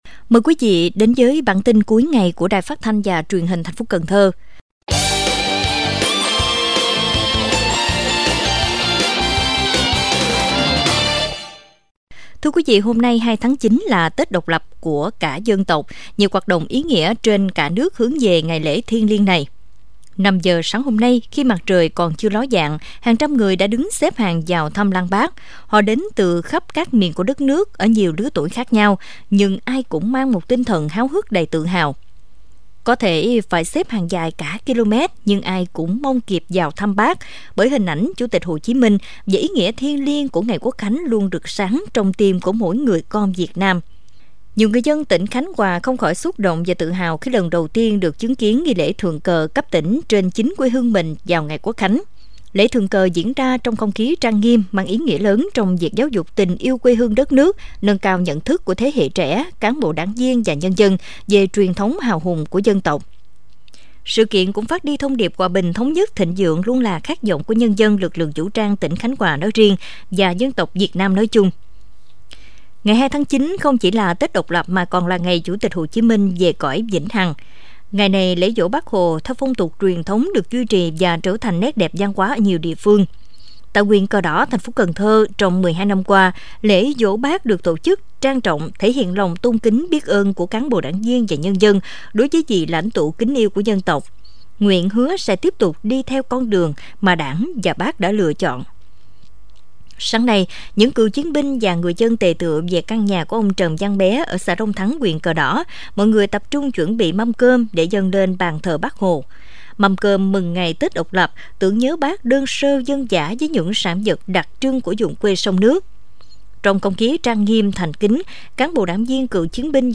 Mời quý thính giả nghe Bản tin cuối ngày của Đài Phát thanh và Truyền hình thành phố Cần Thơ.